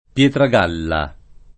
[ p LH tra g# lla ]